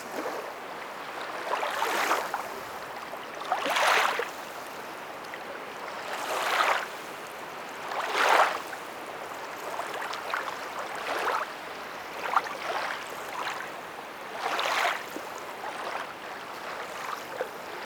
LAKE LAP 01L.wav